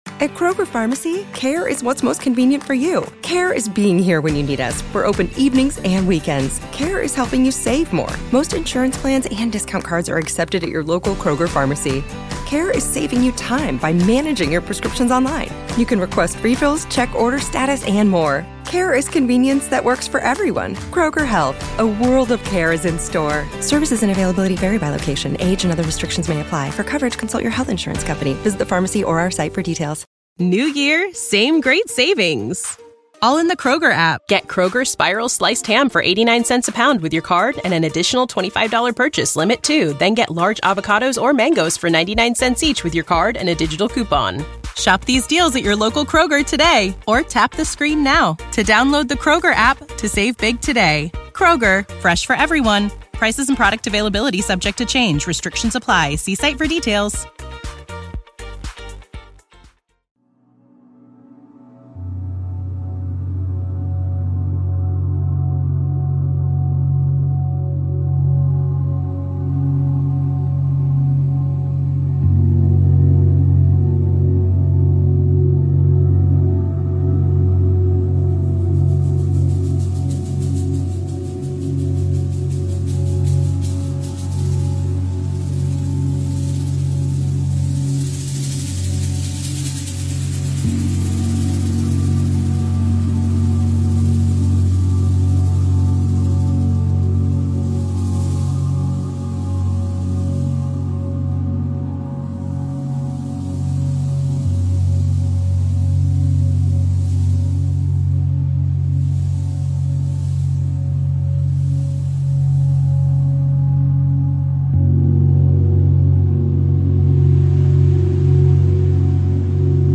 The wind rises and falls.